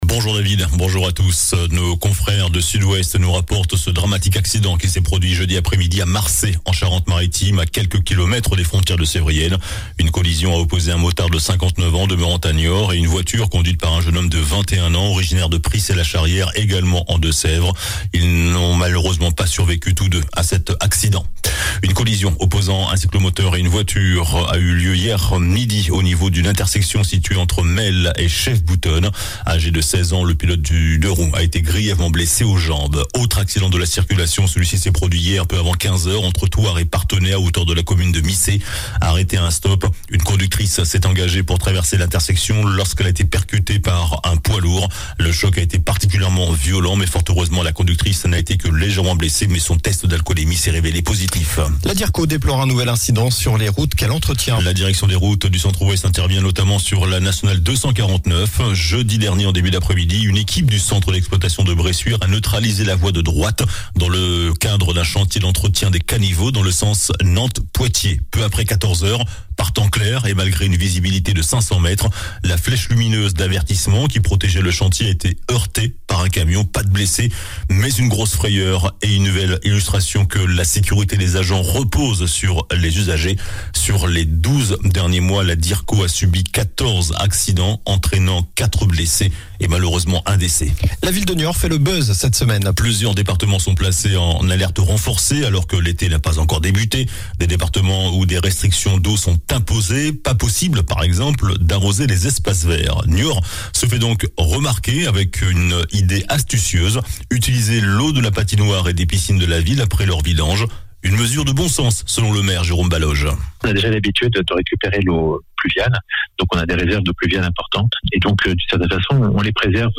JOURNAL DU SAMEDI 04 JUIN